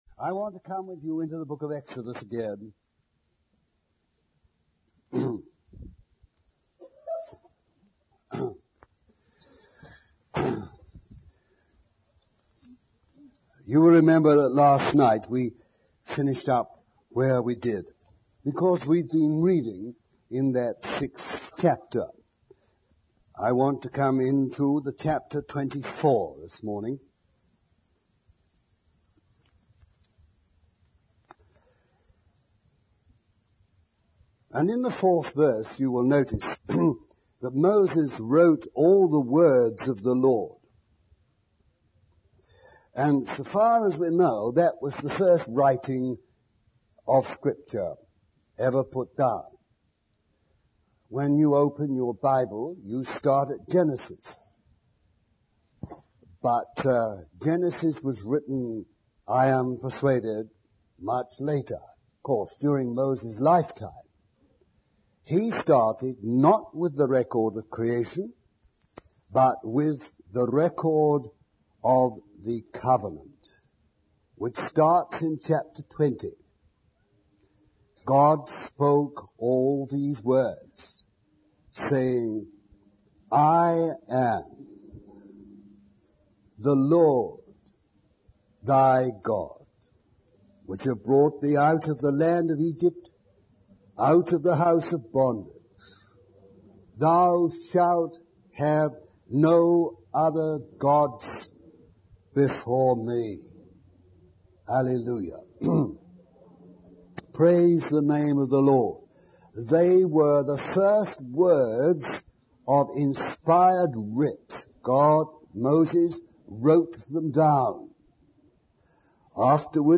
Message
A Deeper Spiritual Experience, Rora, 1987